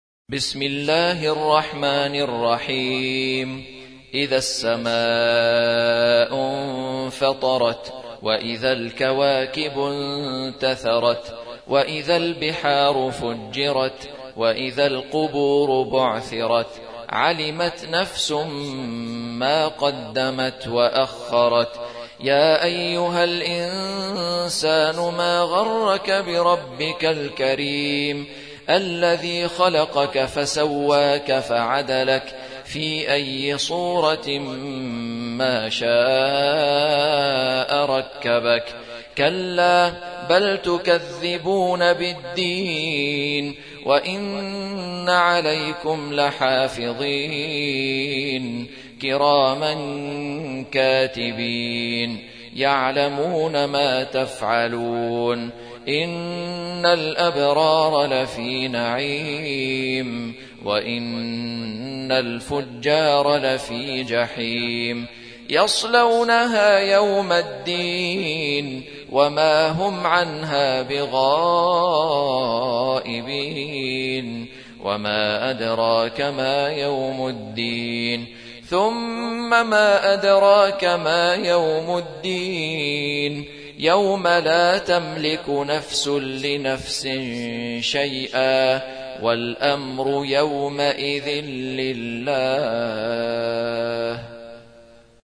82. سورة الانفطار / القارئ